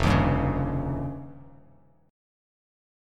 F7sus2#5 chord